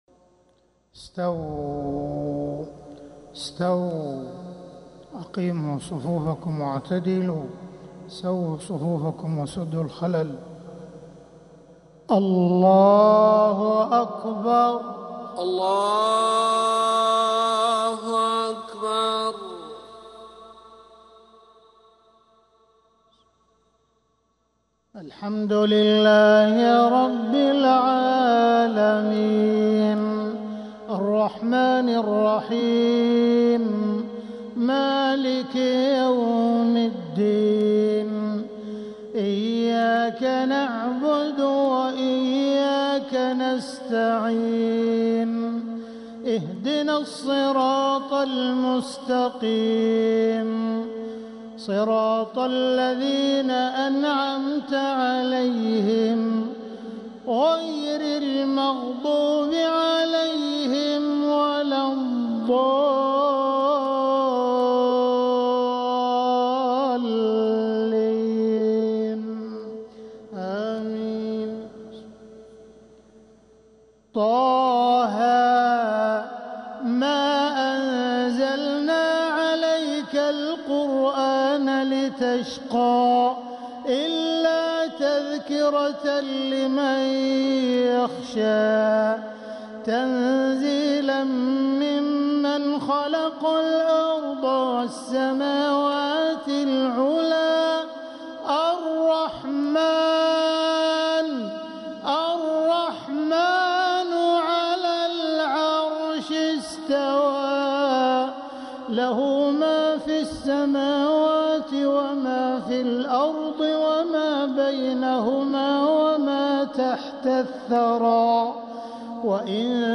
مغرب الجمعة 9 محرم 1447هـ فواتح سورة طه1-16 | Maghrib prayer from Surah Ta-Ha 4-7-2025 > 1447 🕋 > الفروض - تلاوات الحرمين